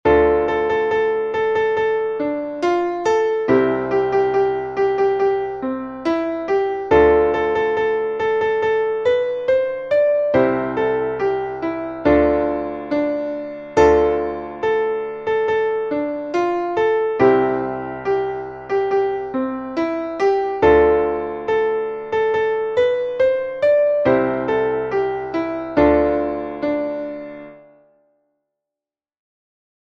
Traditionelles Shanty / Seemannslied